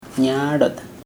[ɲaʔrot] noun summer
Dialect: Hill Remo